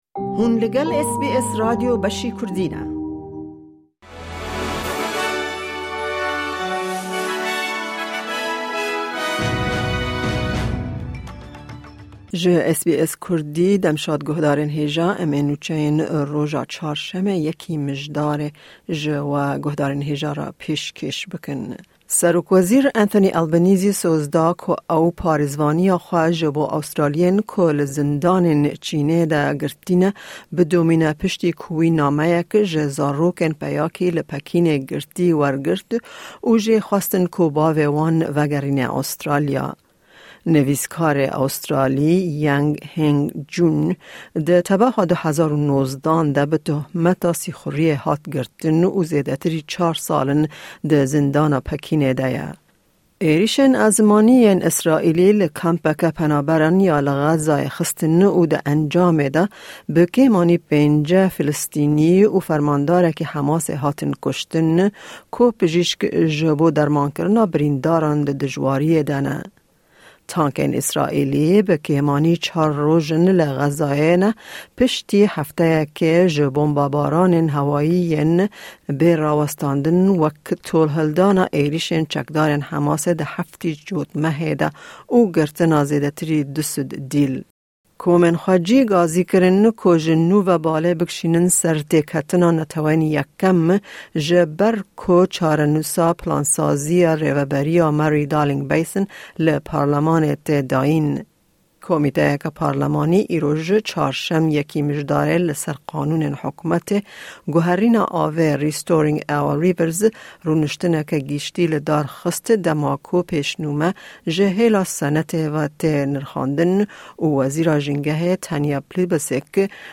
Kurte Nûçeyên roja Çarşemê 1î Mijdara 2023